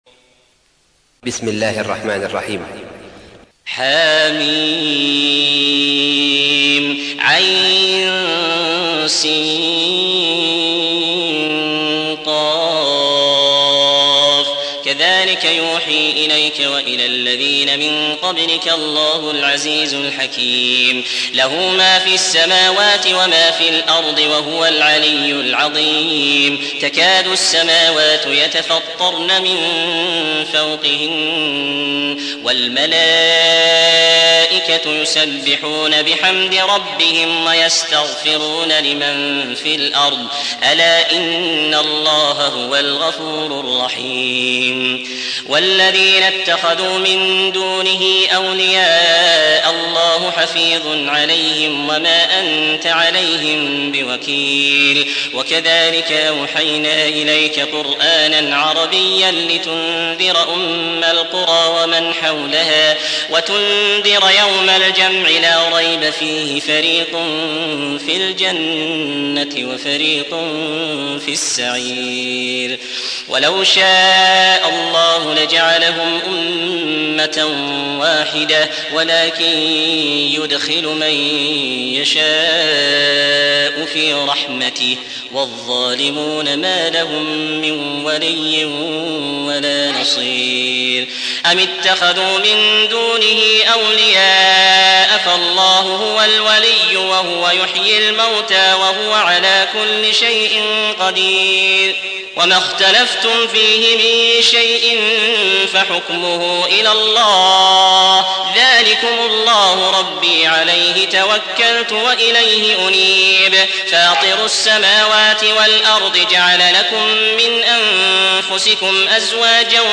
42. سورة الشورى / القارئ